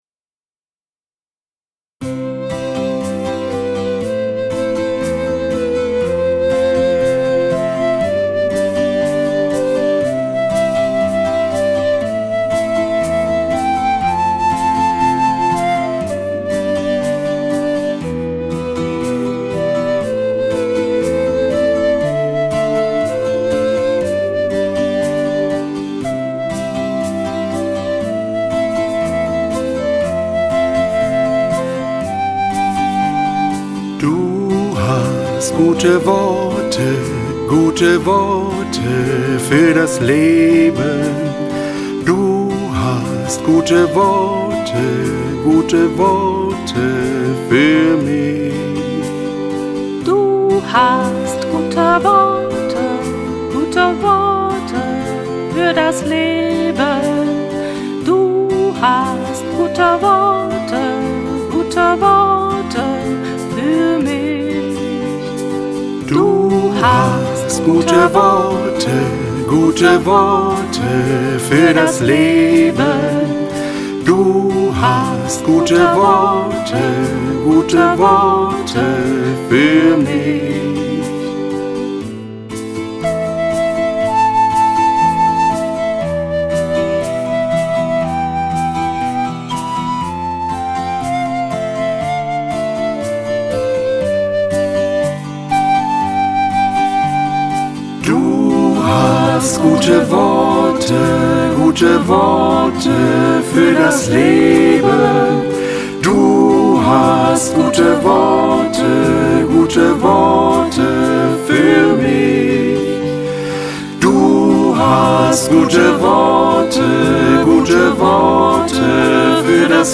Audio - Du hast gute Worte (Taizé-Fassung)
Du_hast_gute_Worte__Taize-fassung.mp3